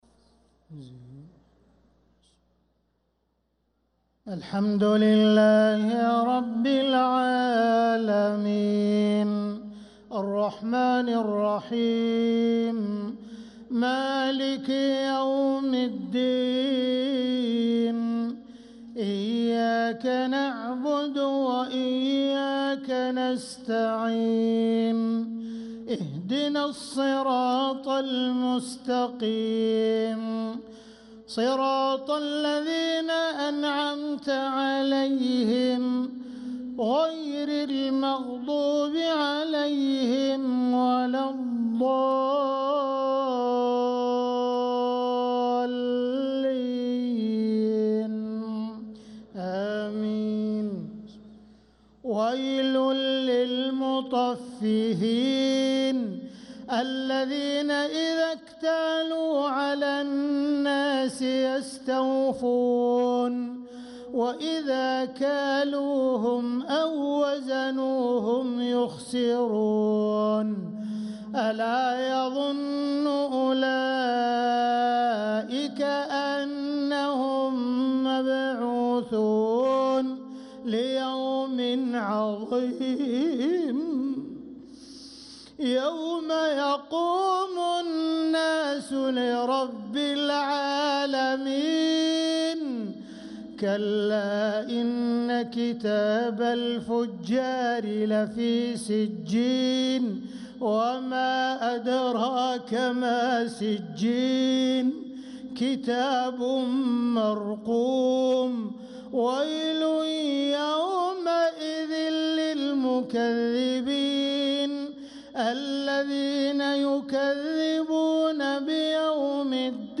صلاة العشاء للقارئ عبدالرحمن السديس 15 رجب 1446 هـ
تِلَاوَات الْحَرَمَيْن .